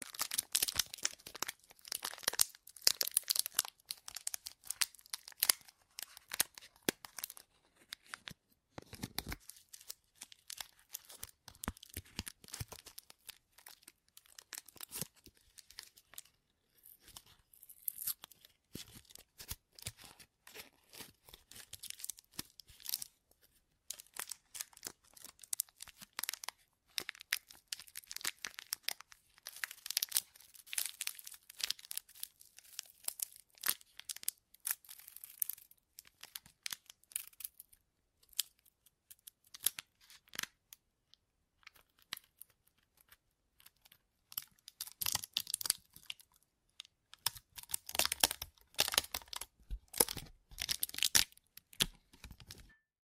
Звук разворачивания конфет из обертки